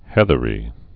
(hĕthə-rē)